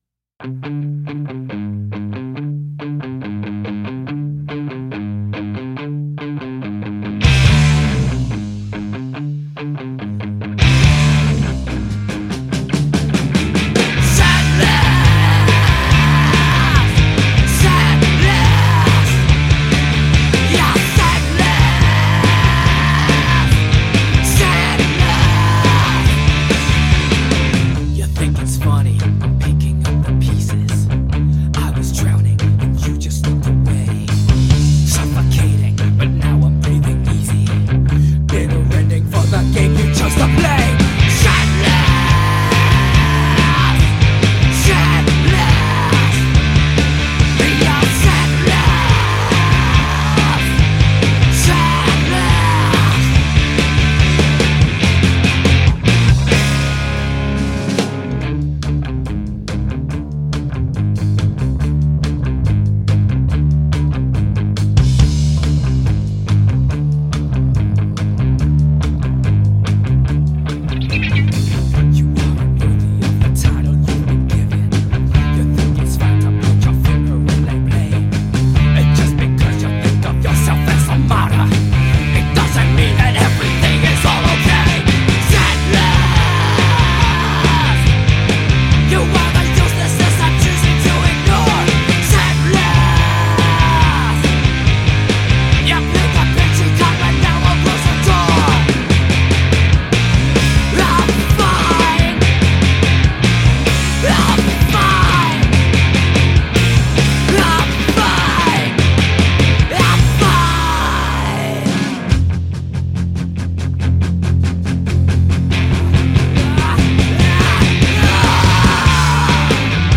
Punk/Rock band